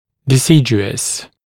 [dɪ’sɪdjuəs][ди’сидйуэс]временный, молочный (о зубе)